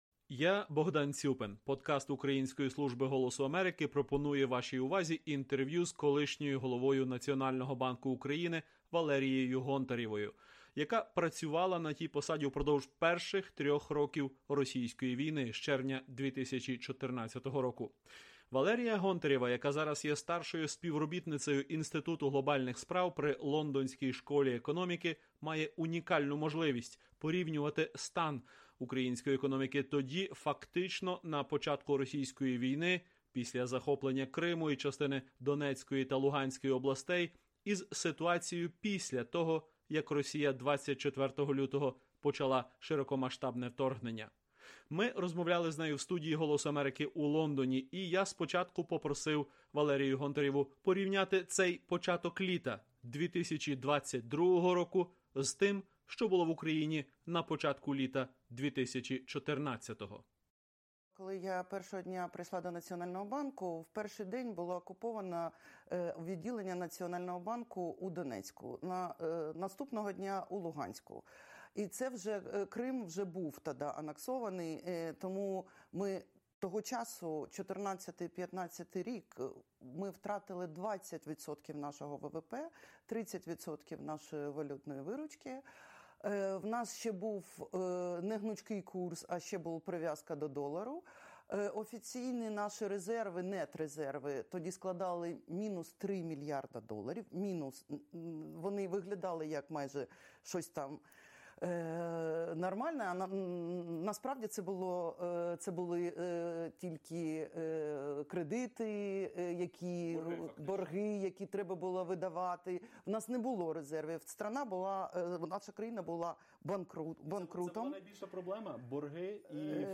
Колишня голова Національного банку України Валерія Гонтарева в інтерв’ю Голосу Америки пригадує, як український уряд намагався стабілізувати економіку на початку російської війни 2014 року і ділиться впевненістю, що міжнародна підтримка допоможе Україні перемогти й відбудуватися.